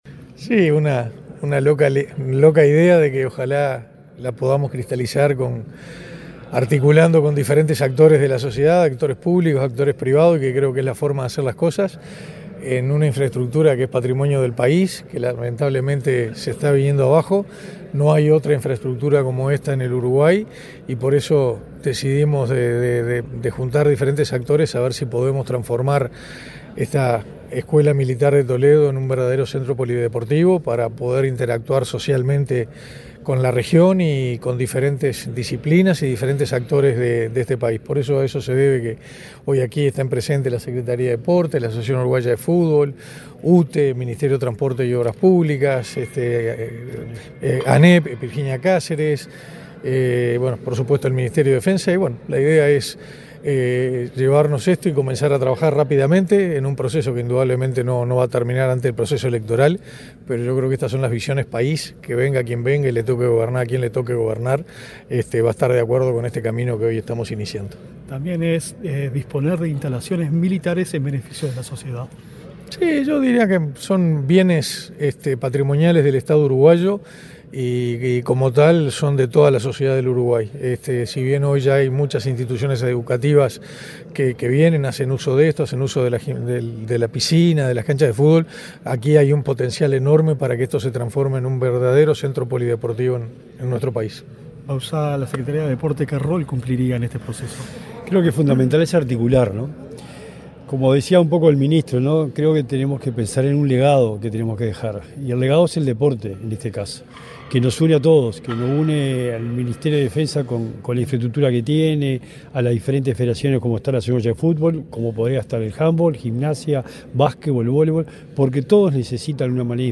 Declaraciones de autoridades en Toledo